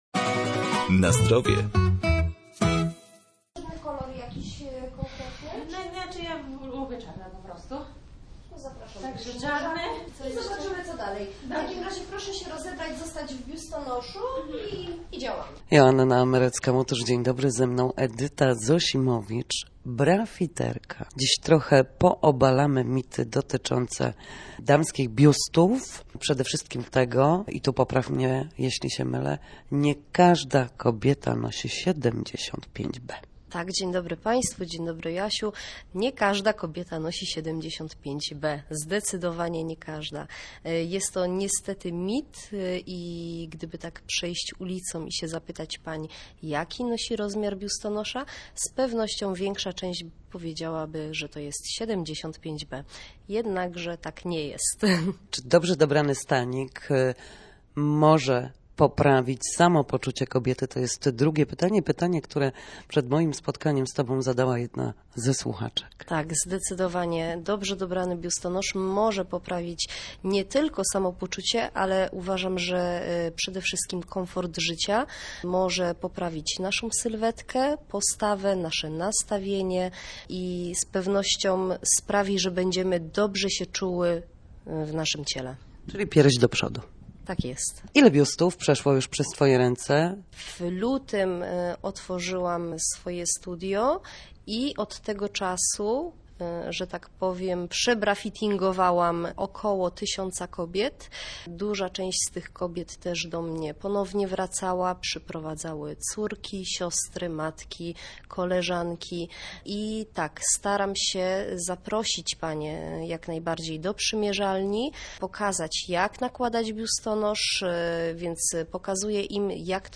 W poniedziałki o godzinie 7:20, a także po godzinie 14:30 na antenie Studia Słupsk dyskutujemy o tym, jak wrócić do formy po chorobach i urazach. W audycji „Na Zdrowie” nasi goście – lekarze i fizjoterapeuci – odpowiadają na pytania dotyczące najczęstszych dolegliwości, podpowiadają, jak wyleczyć się w domowych warunkach i zachęcają do udziału w nowych, ciekawych projektach.